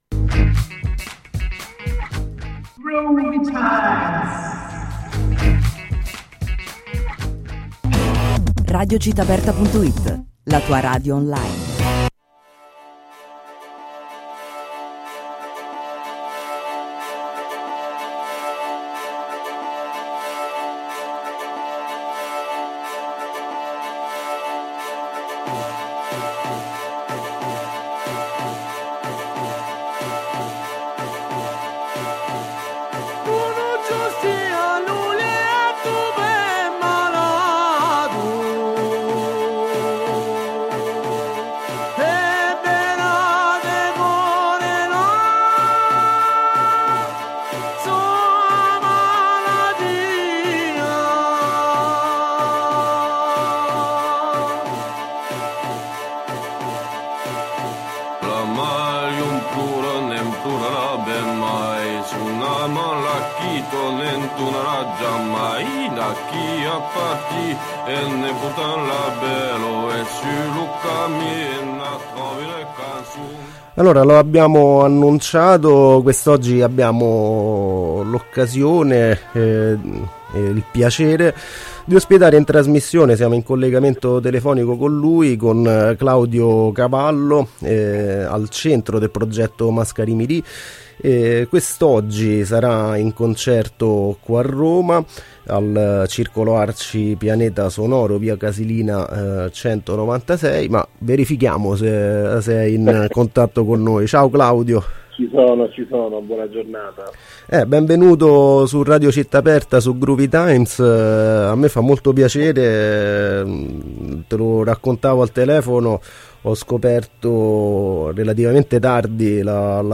groovytimes_intervista_mascarimiri.mp3